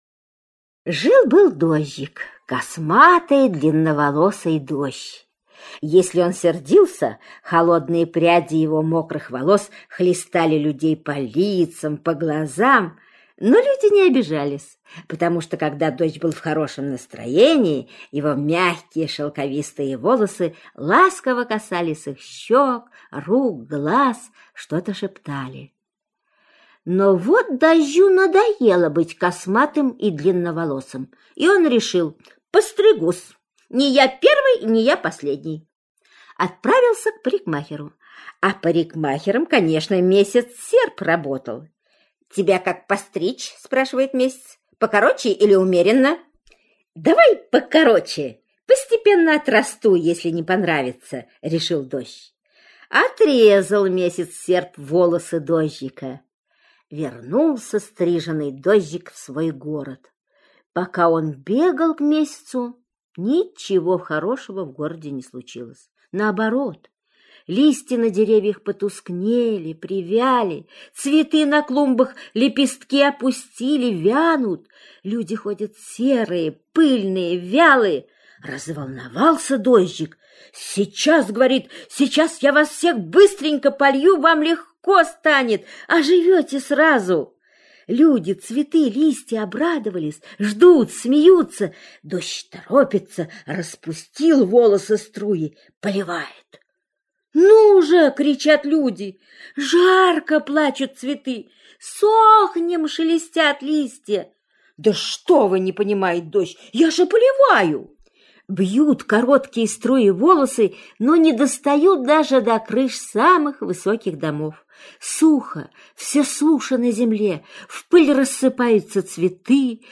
Аудиосказка «Дождик»